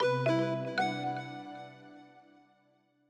Longhorn 9X - Notification Mail.wav